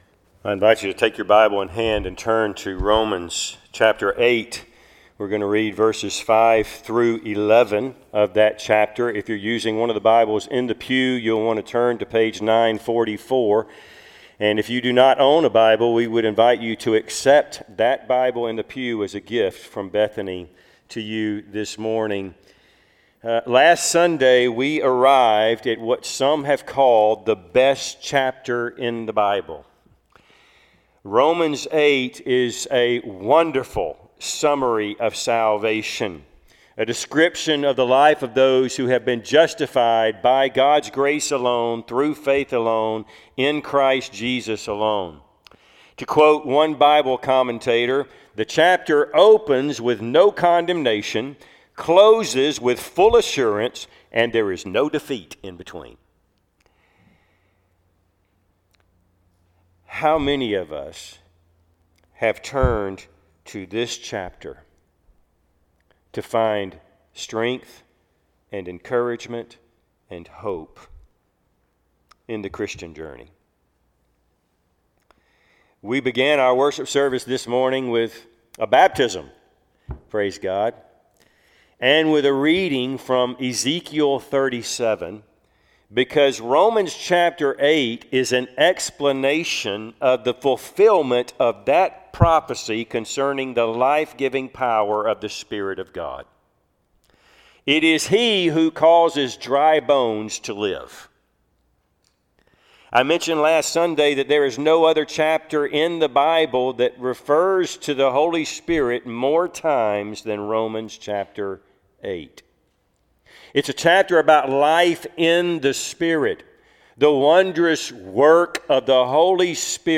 Passage: Romans 8:5-11 Service Type: Sunday AM